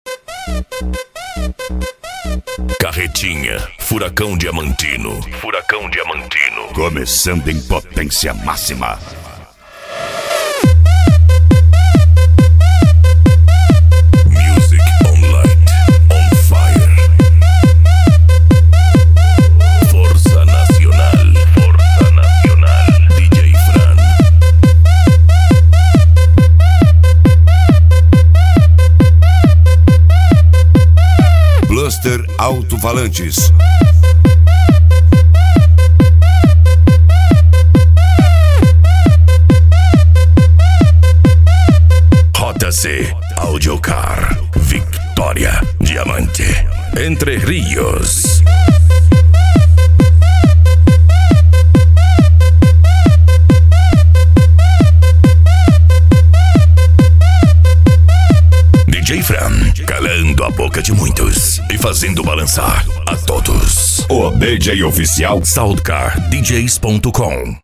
Bass
PANCADÃO
Remix
Trance Music